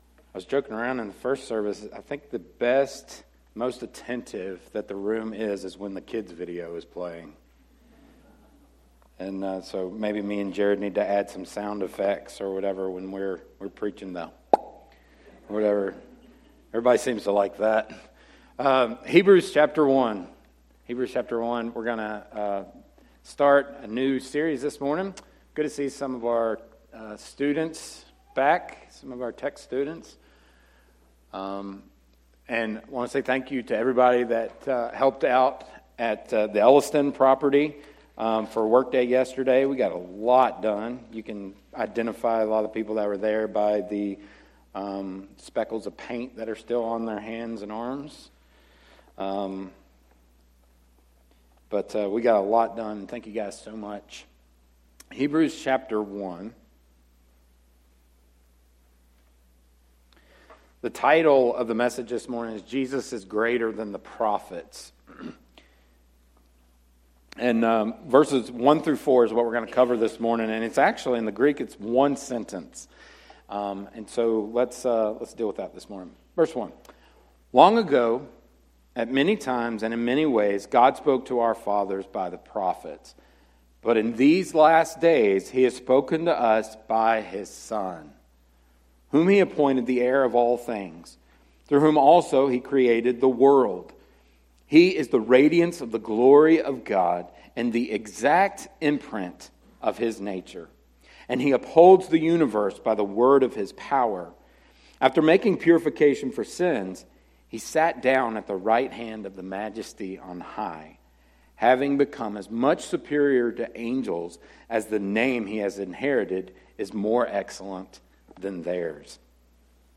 sermon-audio-trimmed.mp3